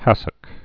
(hăsək)